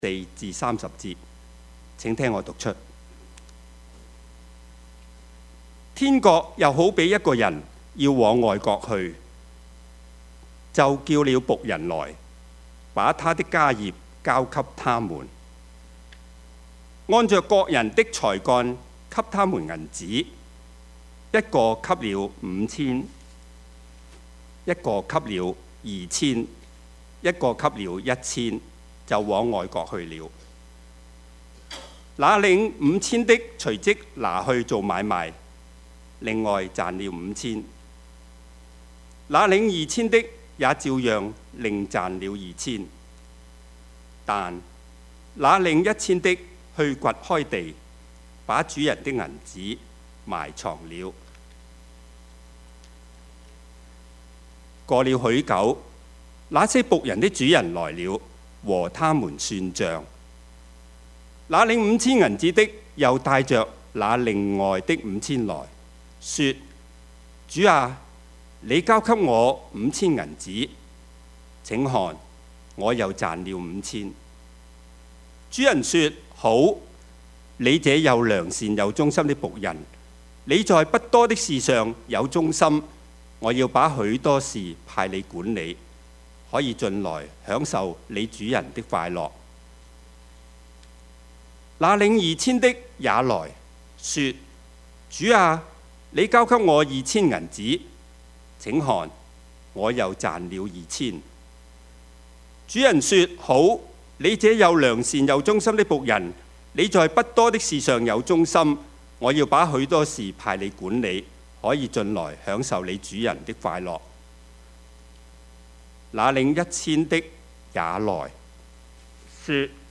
Service Type: 主日崇拜
Topics: 主日證道 « 人間有情 選擇上好的福份 »